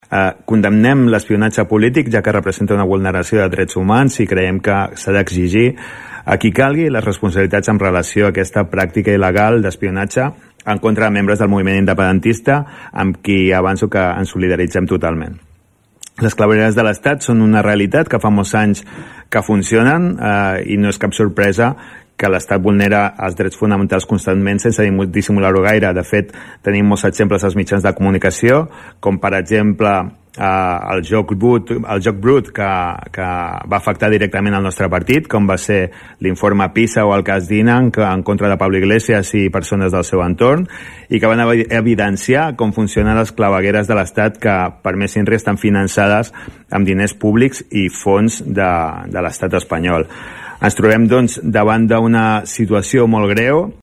El ple de l‘Ajuntament de Tordera va aprovar una moció a favor de demanar al Congrés Espanyol la investigació del CatalanGate, un “espionatge il·legal de l’Estat contra els líders independentistes” que ha destapat el diari “New Yorker”.
El regidor Salvador Giralt presenta el vot favorable.